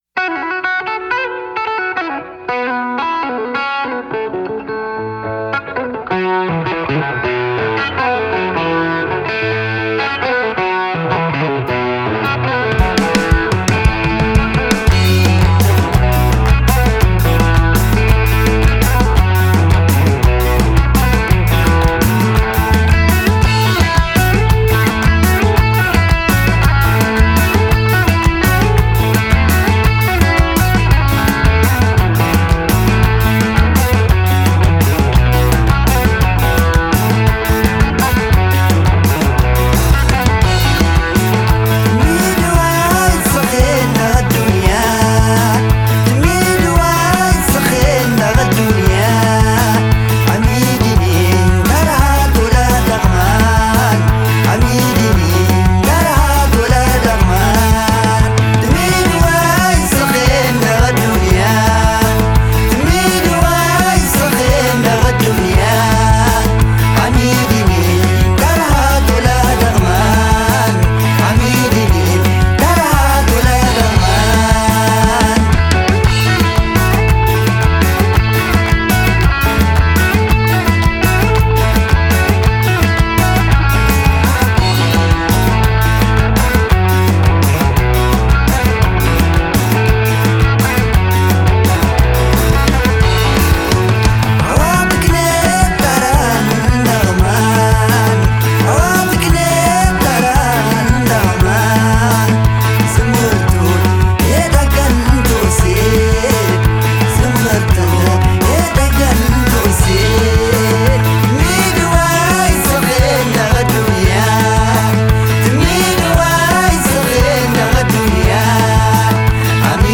Genre: Desert Blues, Folk, World